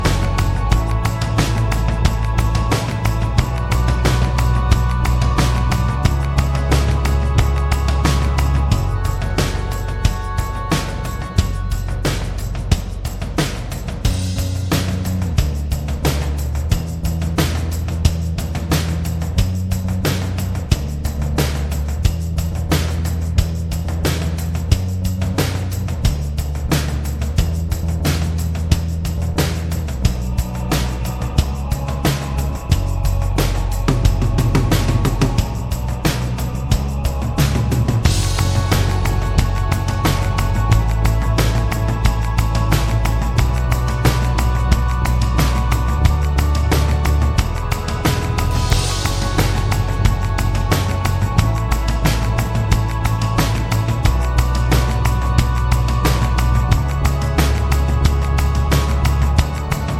Minus Main Guitar For Guitarists 3:18 Buy £1.50